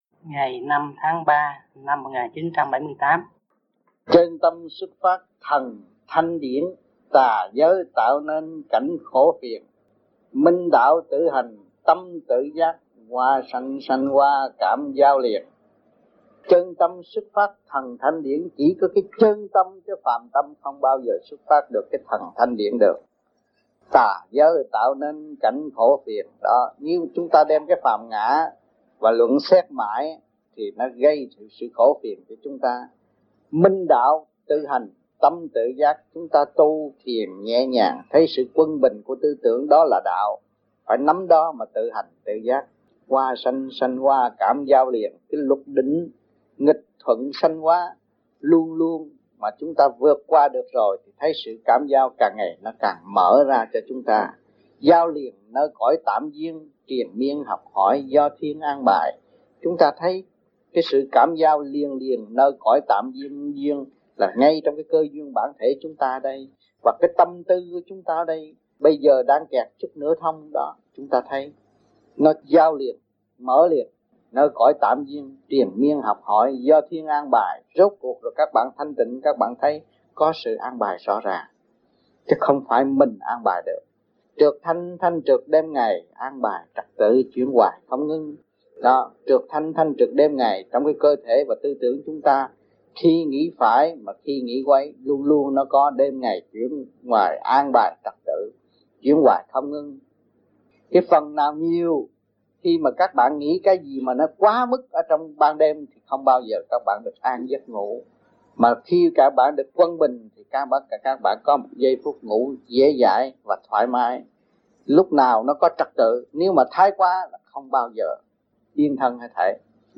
VẤN ĐẠO
THUYẾT GIẢNG